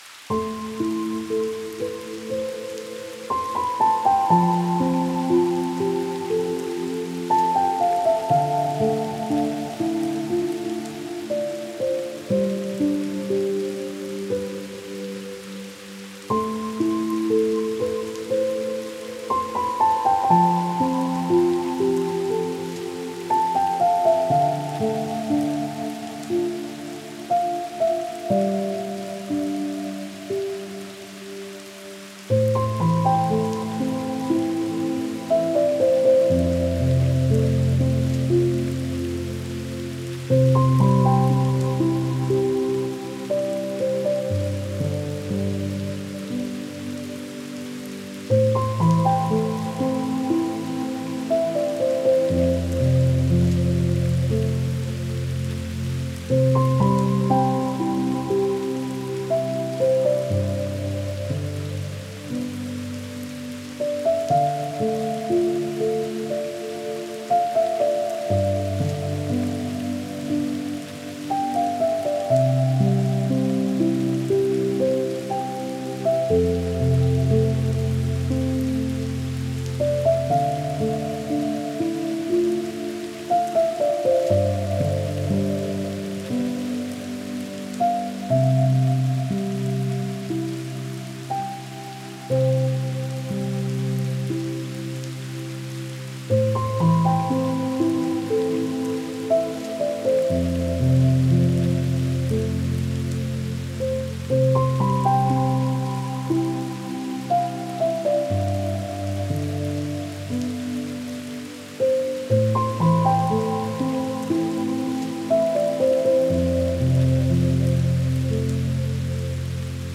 это трек в жанре поп с элементами электронного звучания